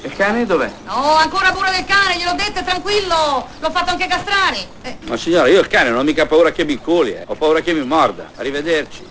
Can che abbaia non morde!!!!
cane-84082.wav